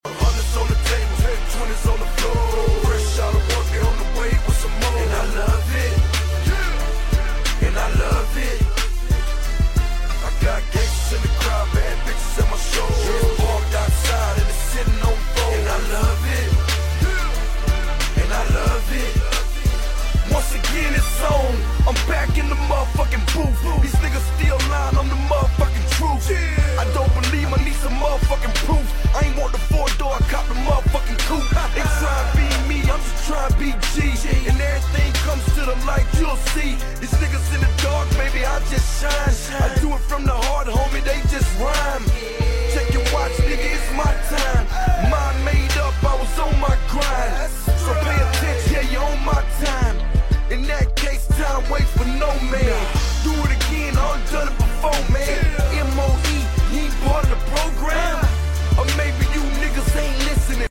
This one houses 4 DD Audio SL710 Subwoofers. Box is Ported and Tuned to 35hz with a Plexi Viewing Window & LEDs Inside!